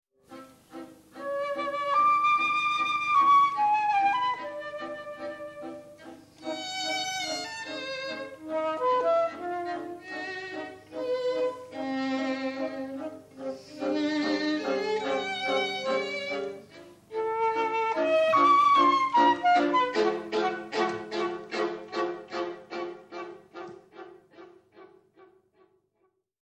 für Flöte, Violine u. Viola (1988)